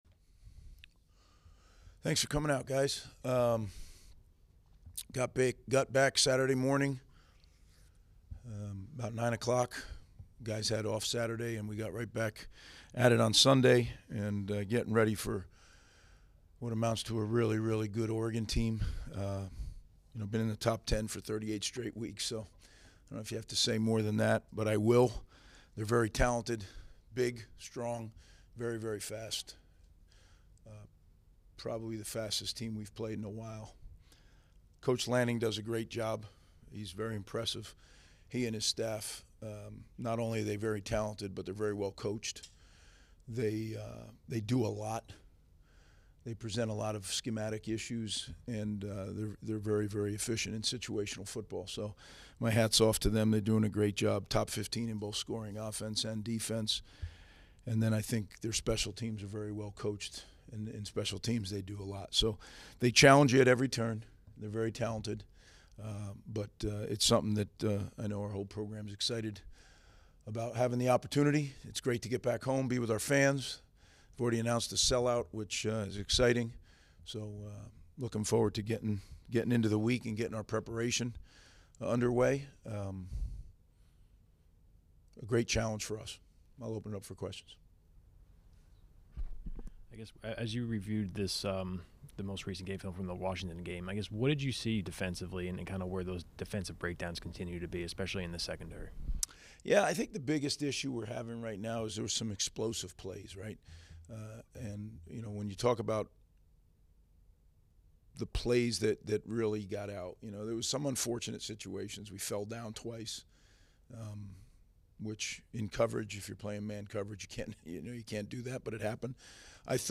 Game Week Press Conference: Head Coach Greg Schiano - 10/13/25 - Rutgers University Athletics